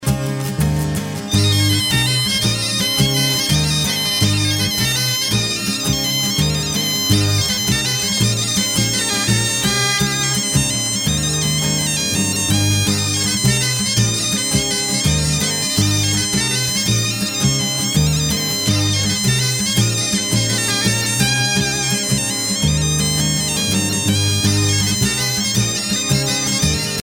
danse : kost ar c'hoad
Pièce musicale éditée